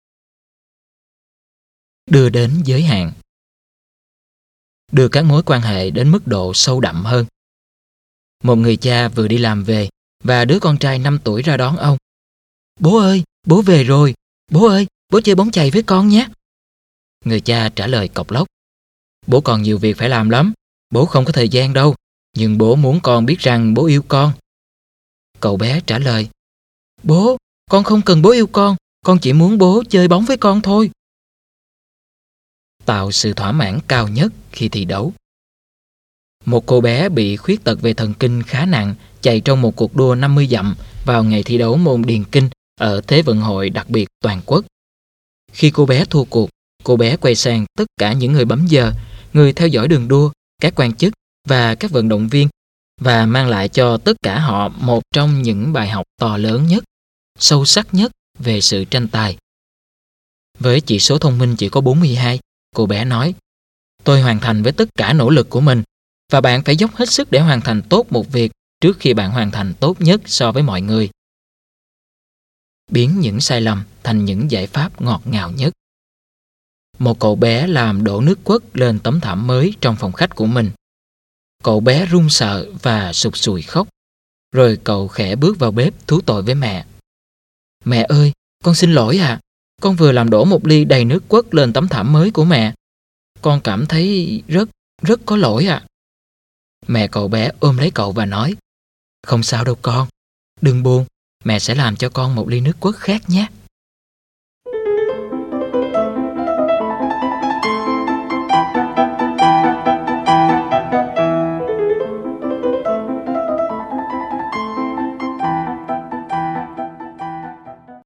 Sách nói Chicken Soup 14 - Quà Tặng Từ Trái Tim - Jack Canfield - Sách Nói Online Hay